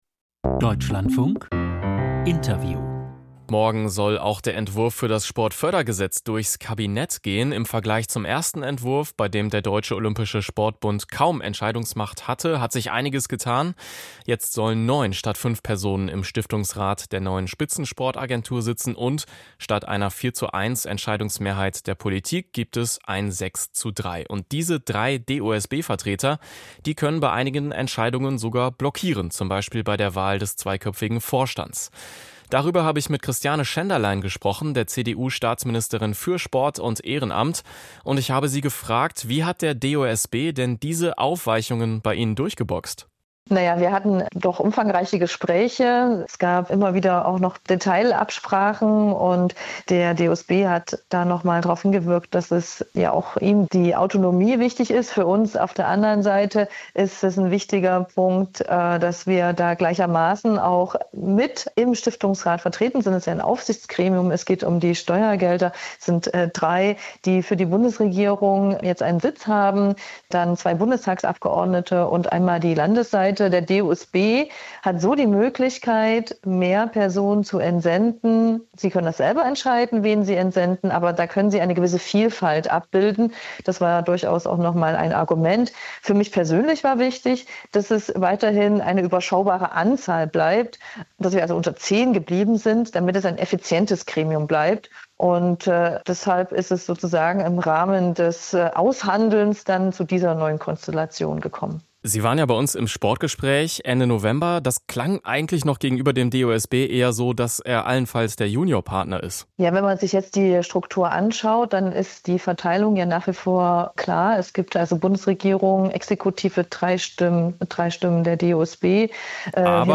Interview Staatsministerin Schenderlein (CDU) zu Sportfördergesetz im Kabinett